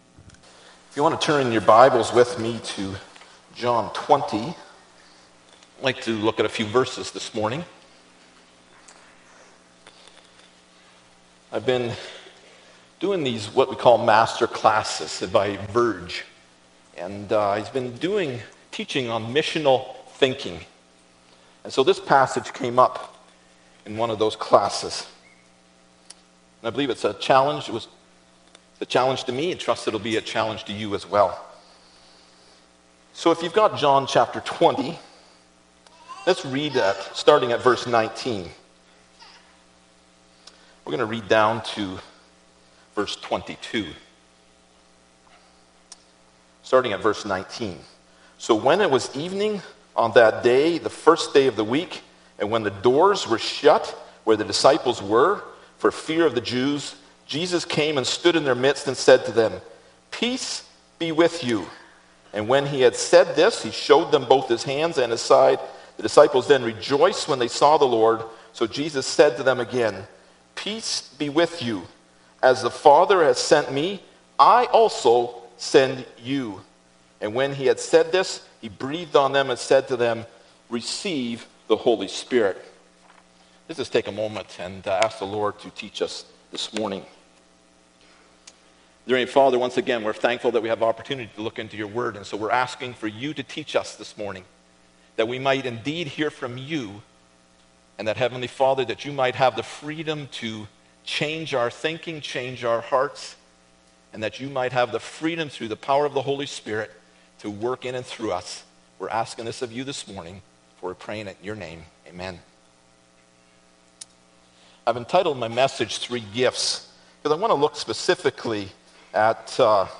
John 20:19-21 Service Type: Sunday Morning Bible Text